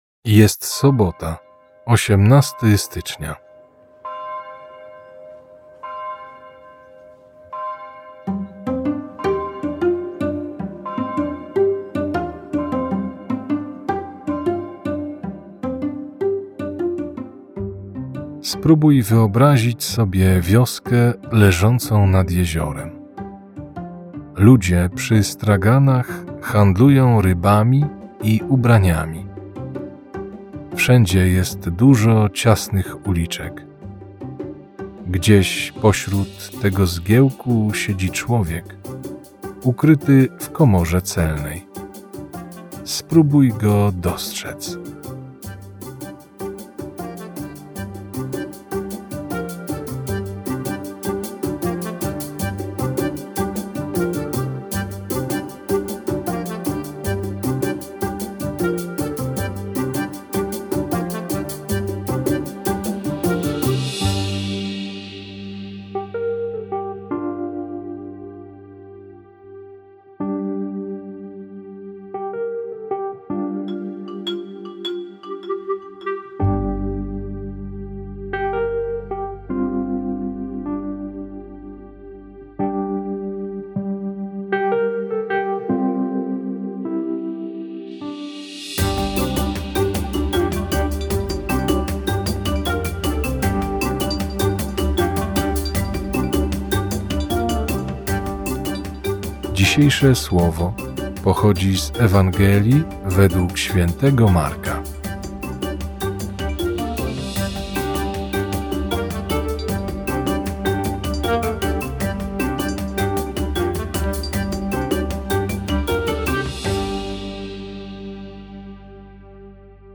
Polscy Jezuici, idąc za przykładem swoich braci z Wielkiej Brytanii, zaproponowali serie około dziesięciominutowych rozważań łączących muzykę i wersety z Biblii, pomagające odkrywać w życiu Bożą obecność i pogłębić relacje z Bogiem.